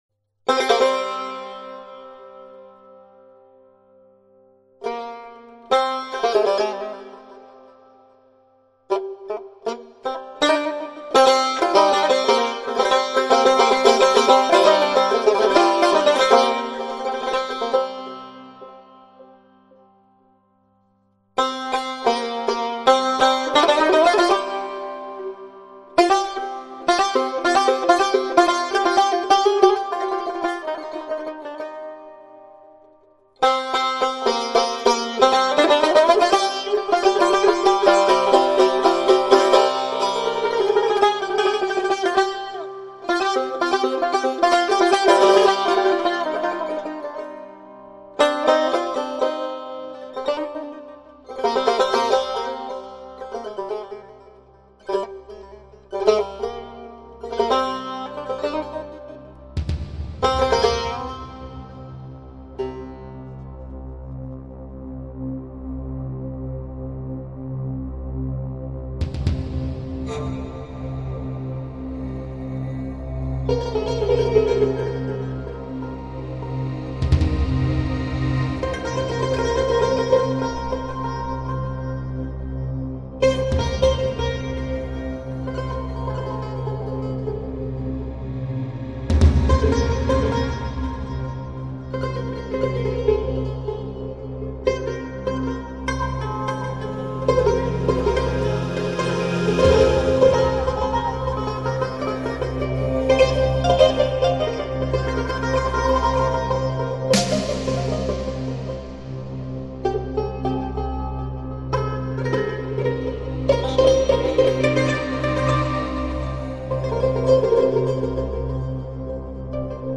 مناجات | تعالیم و عقاید آئین بهائی
in seda va in melodie besiar ziba,mohebate elahist,moteshakeram